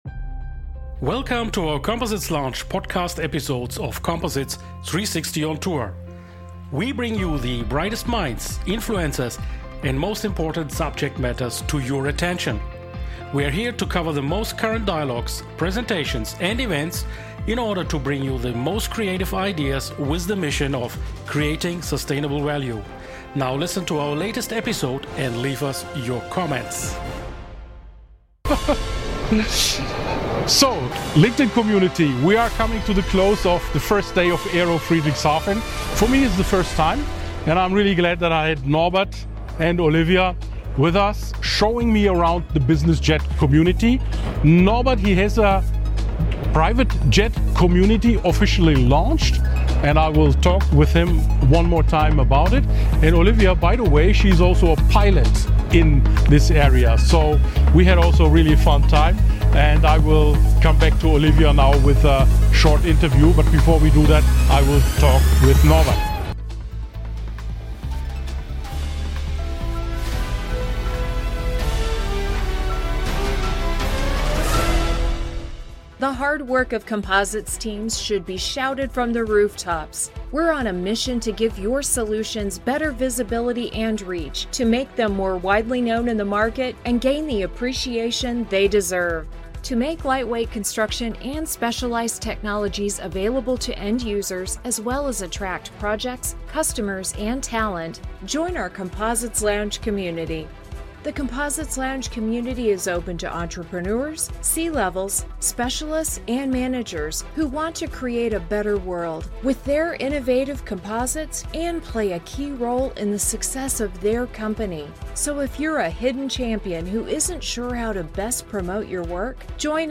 What a day at AERO Friedrichshafen—Europe’s premier general aviation show, now boldly stepping into the bus...